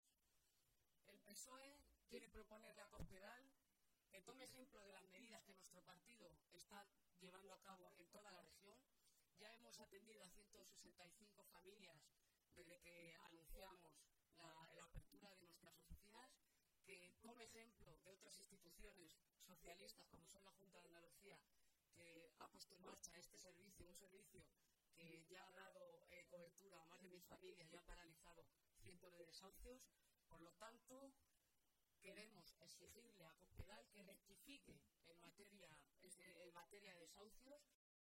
Momento de la rueda de prensa anterior a las jornadas